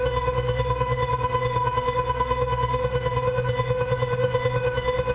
Index of /musicradar/dystopian-drone-samples/Tempo Loops/140bpm
DD_TempoDroneB_140-B.wav